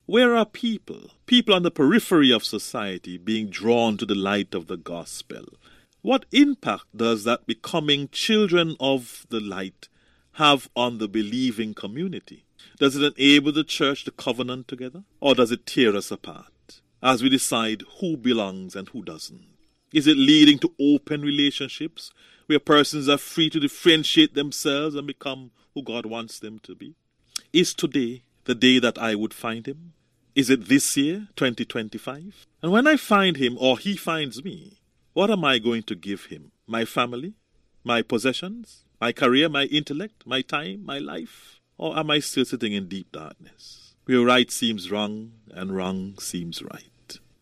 As the new year progresses, a few New Year’s Messages were heard last Sunday by members of various church denominations in St. Kitts and Nevis on VON Radio.